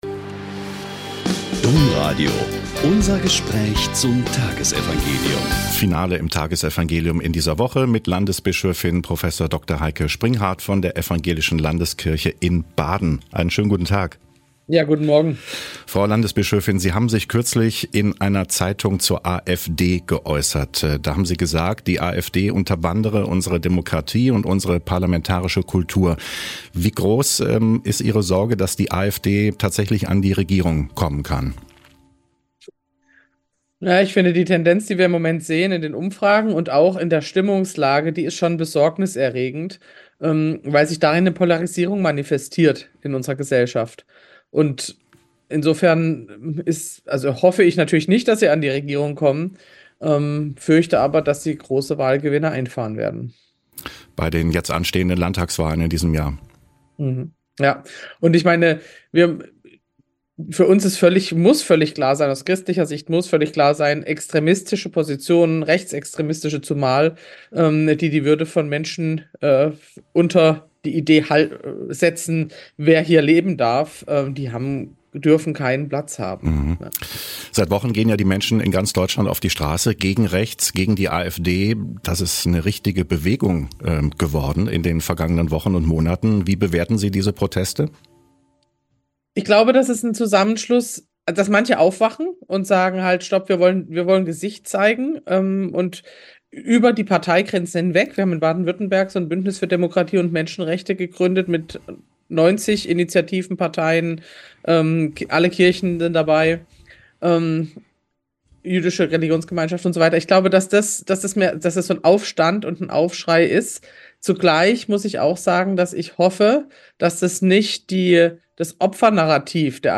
Joh 7, 40-53 - Gespräch mit Landesbischöfin Prof. Dr. Heike Springhart